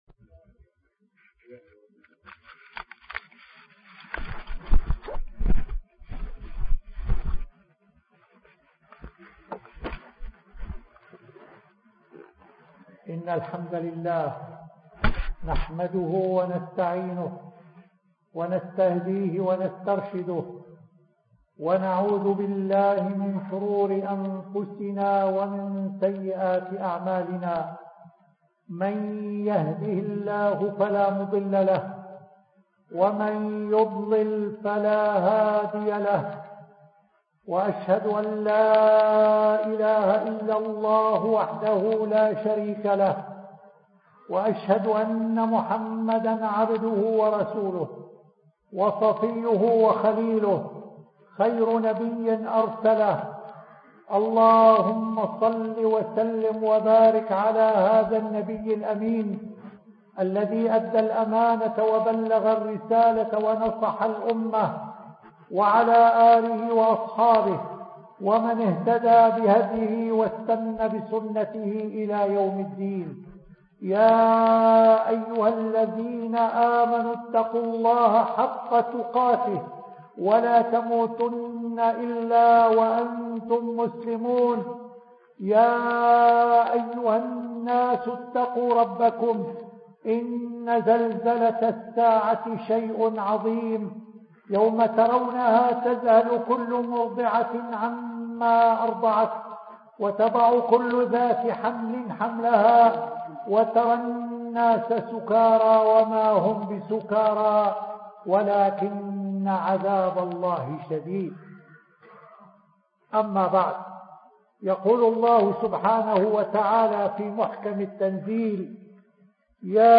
تم إلقاء هذه الخطبة في مسجد النعمان بن بشير الدوحة قطر تاريخ إلقاء الخطبة 6 ربيع الأول 1426 الخطبة مصحوبة كتابياً بصيغة ملف وورد
الخطـب المنبريــة